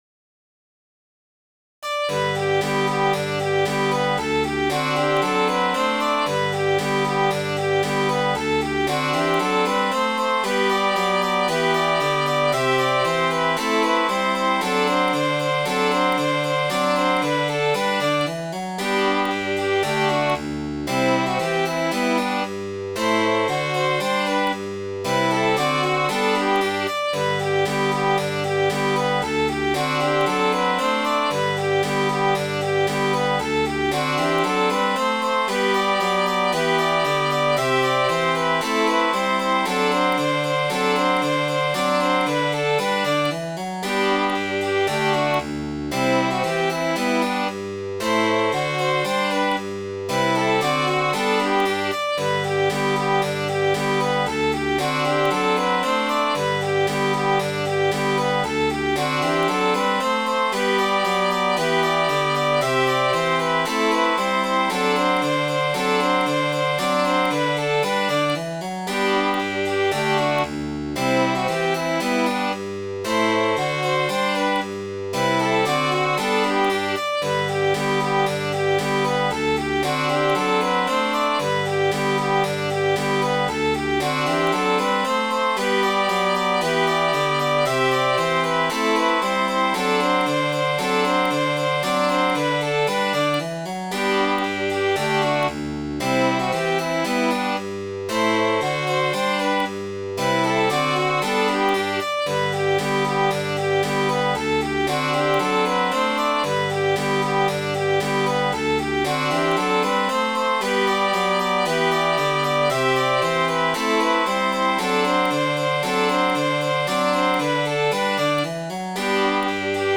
Midi File, Lyrics and Information to The Hunters of Kentuckyr